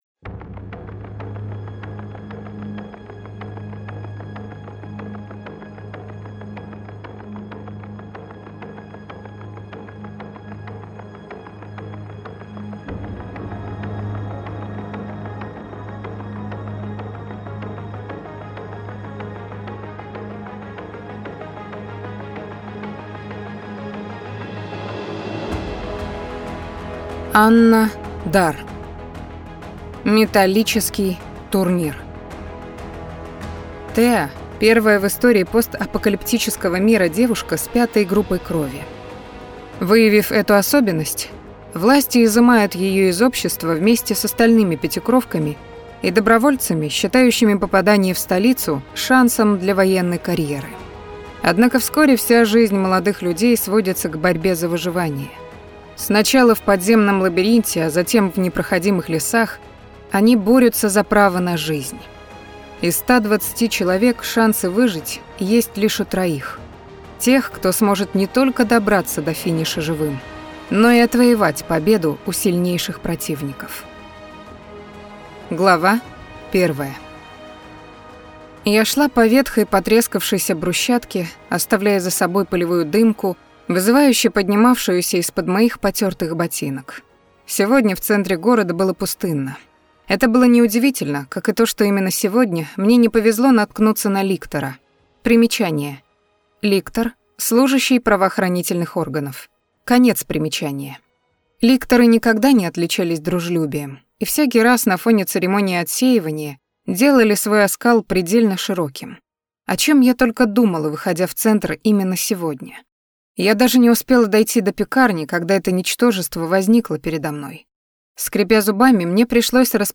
Аудиокнига Металлический Турнир | Библиотека аудиокниг
Прослушать и бесплатно скачать фрагмент аудиокниги